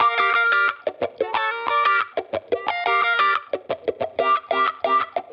Index of /musicradar/sampled-funk-soul-samples/90bpm/Guitar
SSF_StratGuitarProc1_90G.wav